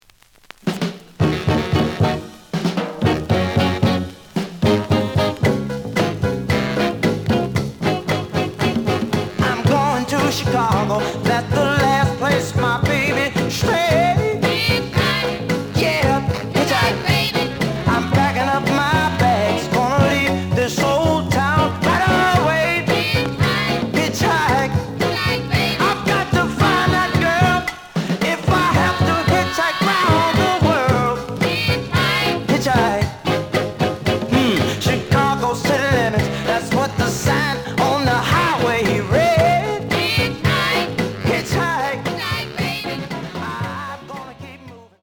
The audio sample is recorded from the actual item.
●Genre: Soul, 60's Soul
Some click noise on latter half of A side due to a bubble.)